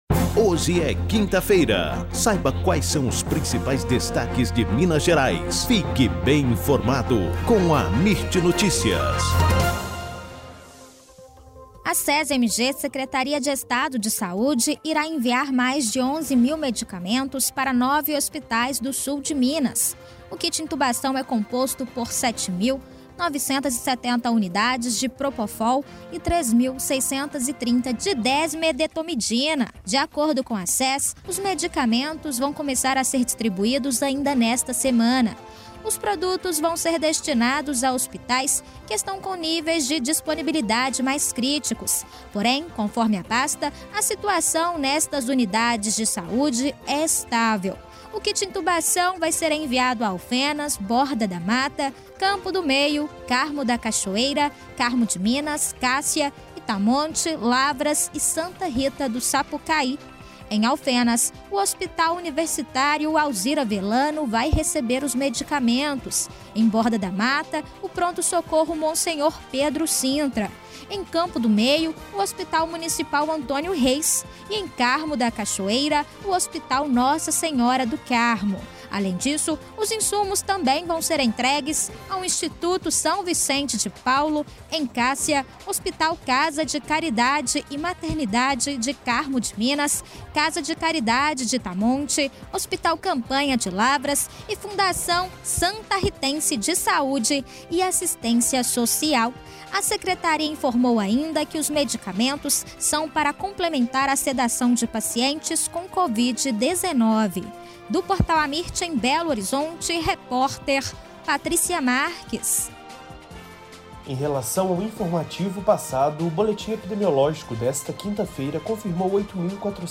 AMIRT Amirt Notícias Destaque Gerais Notícias em áudio Rádio e TelevisãoThe estimated reading time is less than a minute